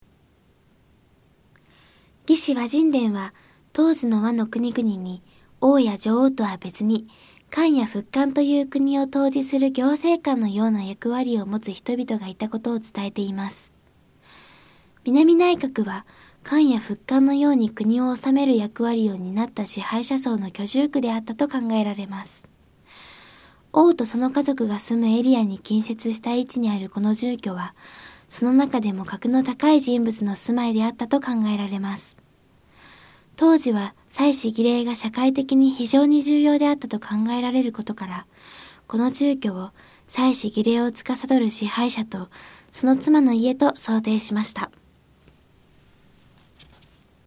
当時は祭祀・儀礼が社会的に非常に重要であったと考えられることから、この住居を祭祀・儀礼を司る支配者とその妻の家と想定しました。 音声ガイド 前のページ 次のページ ケータイガイドトップへ (C)YOSHINOGARI HISTORICAL PARK